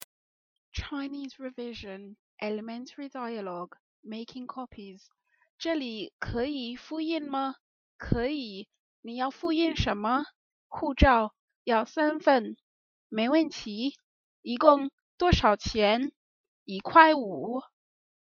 Chinese Pod Audio - Elementary Dialogue: Making Copies